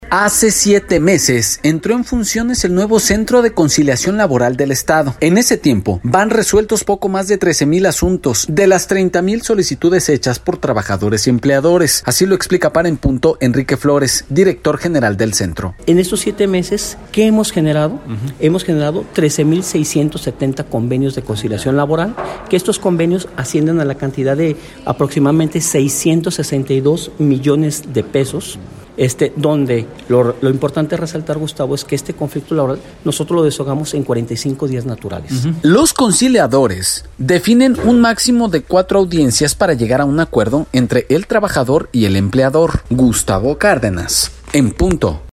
Hace siete meses entró en funciones el nuevo Centro de Conciliación Laboral del Estado, en ese tiempo resolvieron poco más de 13 mil asuntos, de las 30 mil solicitudes hechas por trabajadores y empleadores, informó para el programa En Punto director general, Enrique Flores.